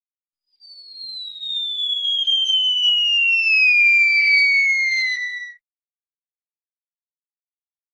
Cartoon Fall Sound Effect Free Download
Cartoon Fall